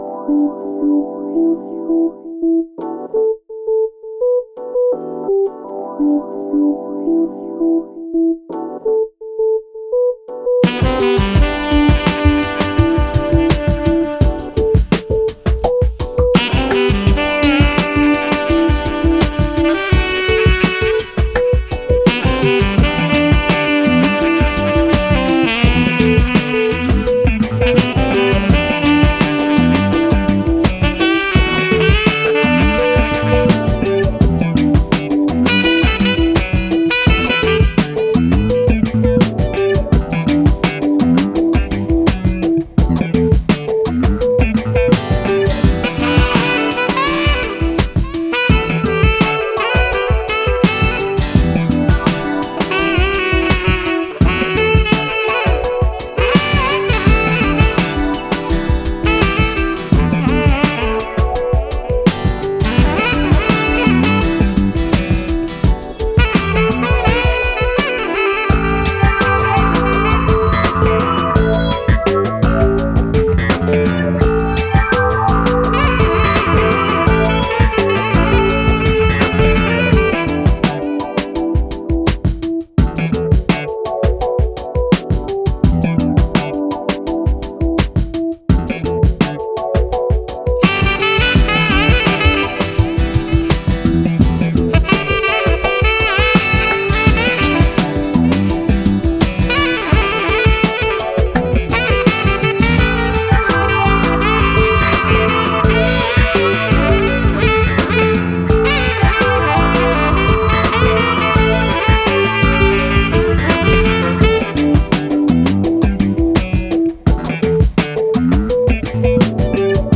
sax and flute
signature bass grooves